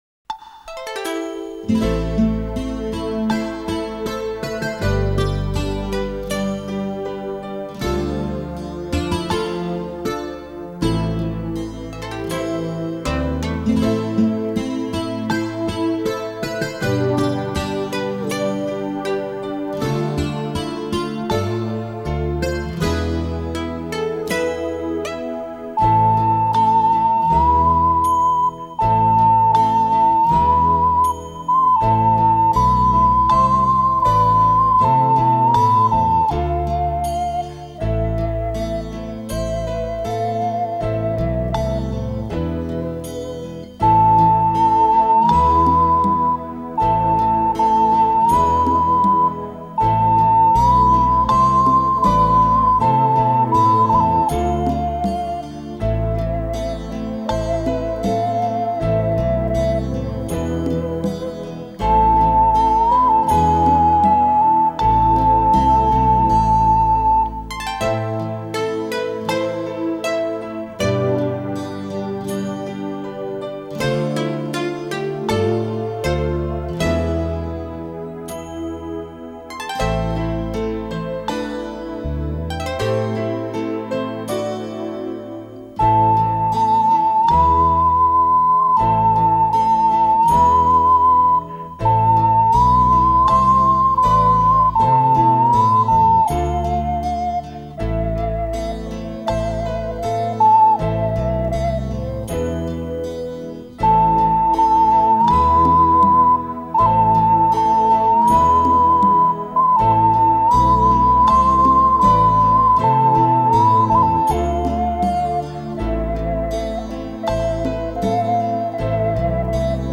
YAponskaya_etnicheskaya_muzyka___Sakura_Sakura_iPleer_.mp3